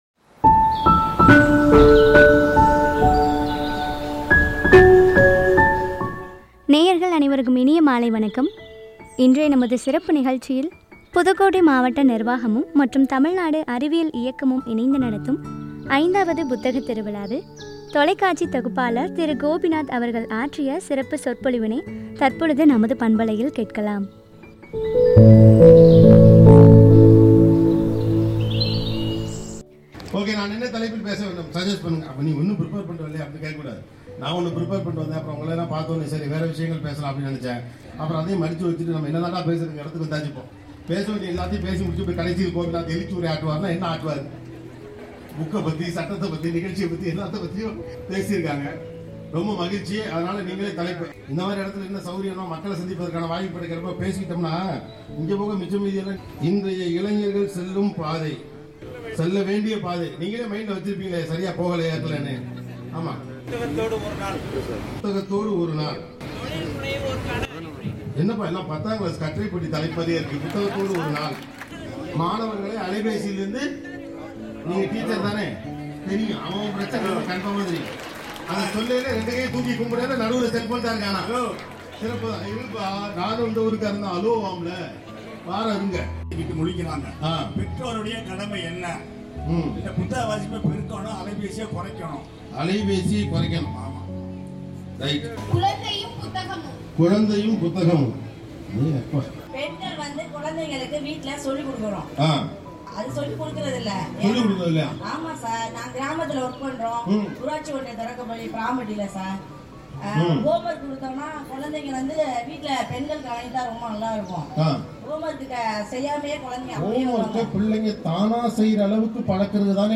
புதுக்கோட்டை 5வது புத்தக திருவிழாவில் தொலைக்காட்சி தொகுப்பாளர் திருமிகு.கோபிநாத் அவர்கள் ஆற்றிய சிறப்பு சொற்பொழிவு.